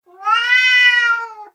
gato3a
cat3a.mp3